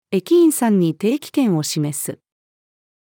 駅員さんに定期券を示す。-female.mp3